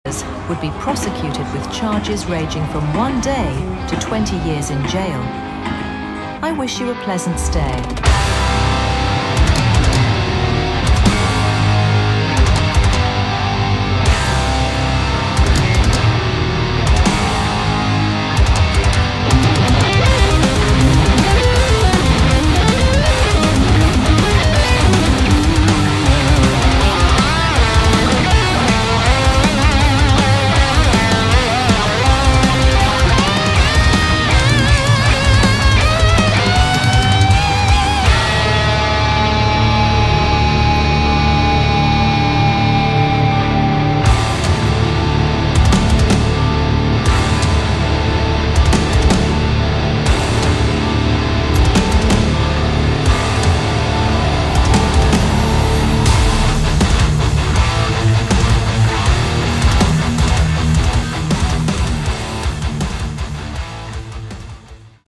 Category: Rock
drums
electric bass
electric guitar
vocals